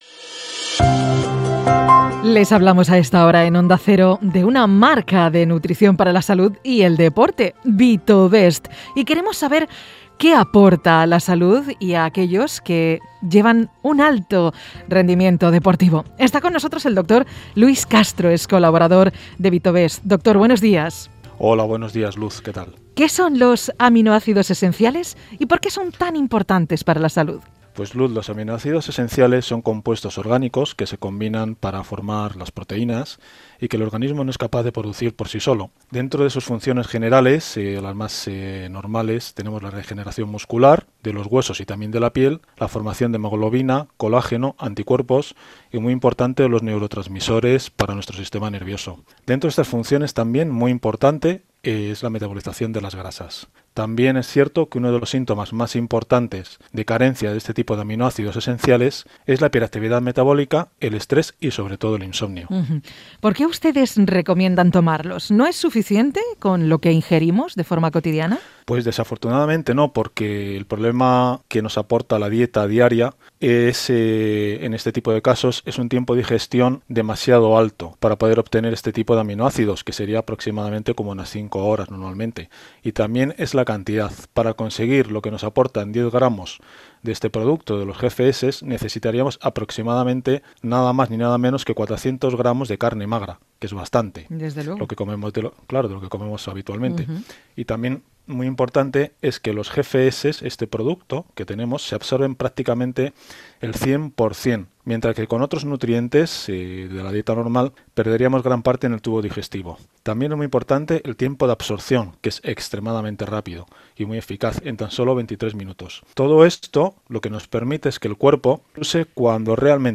Nutrición deportiva / Podcast